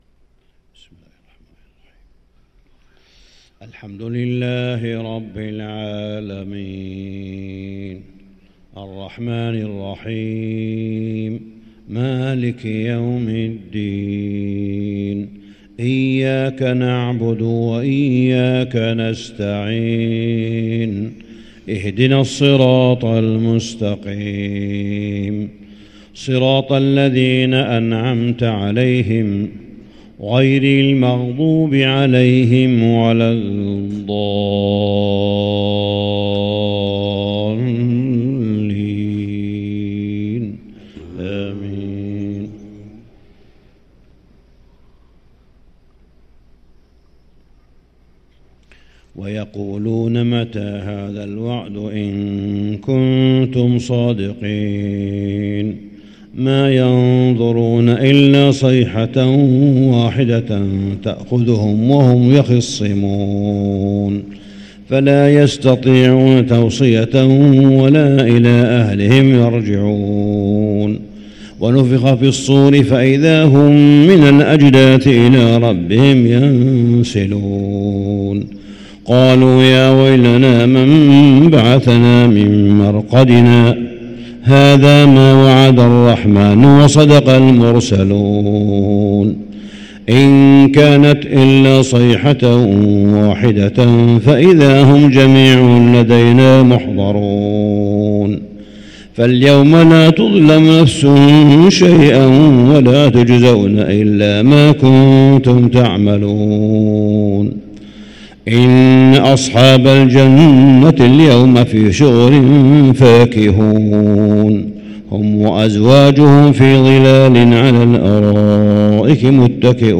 صلاة الفجر للقارئ صالح بن حميد 28 شعبان 1444 هـ